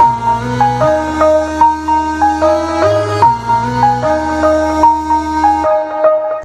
Sizzle SIzz.wav